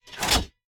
select-cannon-2.ogg